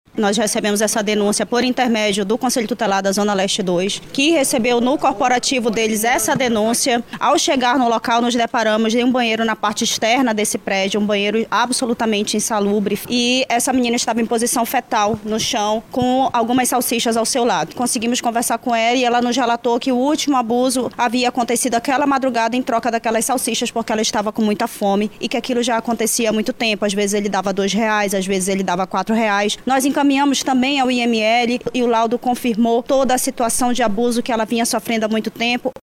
SONORA01_DELEGADA-2.mp3